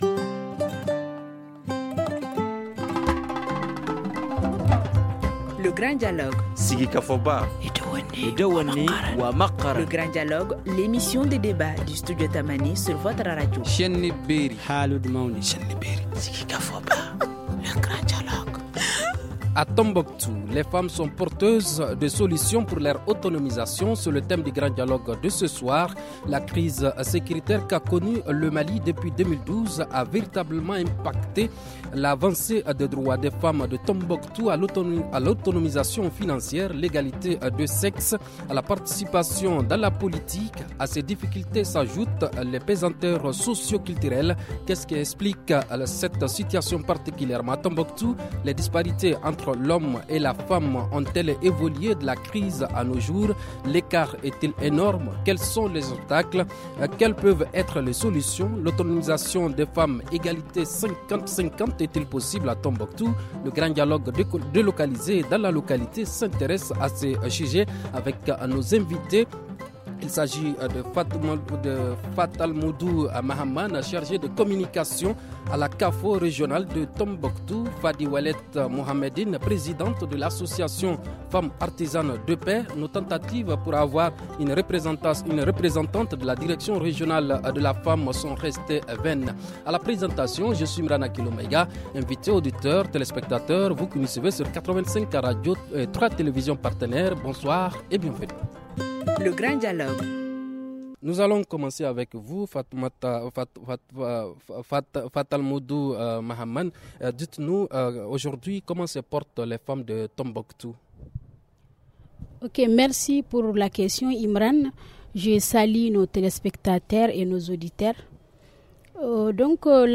Le Grand dialogué délocalisé à Tombouctou s’intéresse à ces sujets.